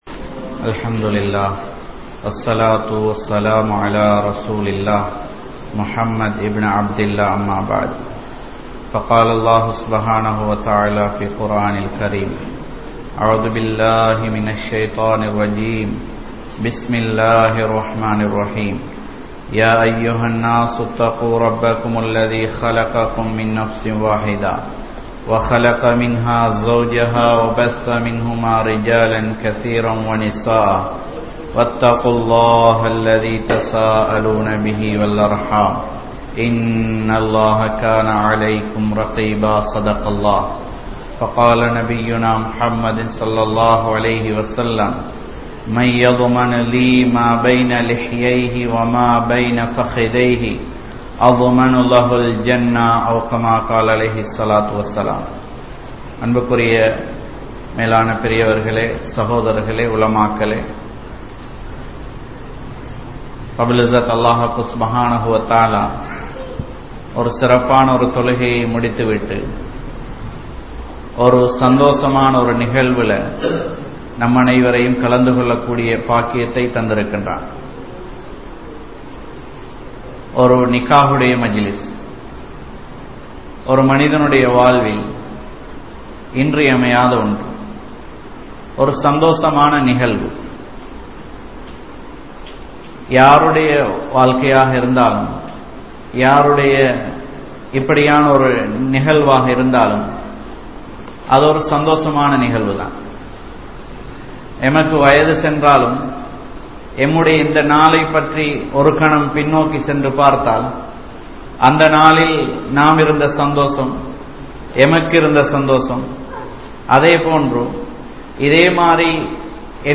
Vifachchaarathin Vilaivuhal (விபச்சாரத்தின் விளைவுகள்) | Audio Bayans | All Ceylon Muslim Youth Community | Addalaichenai
Kurunegala, Mallawapitiya, Masjidhul Hasanath